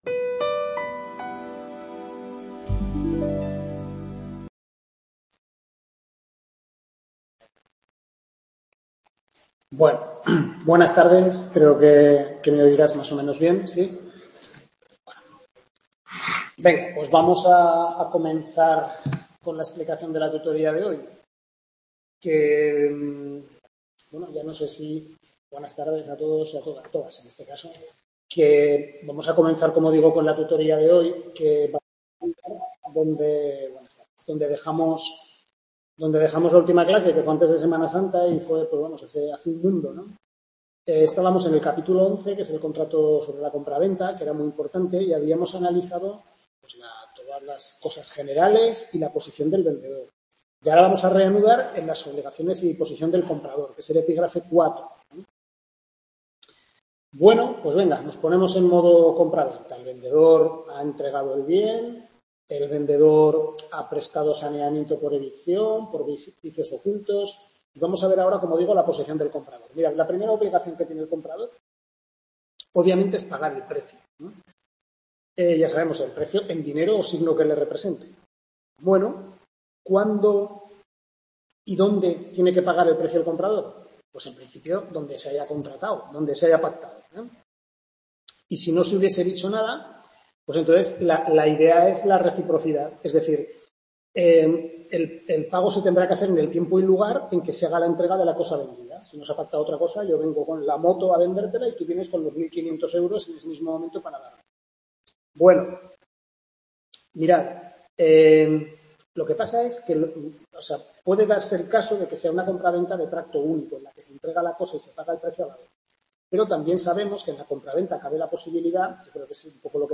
Tutoría 4/6, segundo cuatrimestre de Civil II (Contratos), centro UNED de Calatayud, capítulos 11 (segunda parte) a 15 del Manual del Profesor Lasarte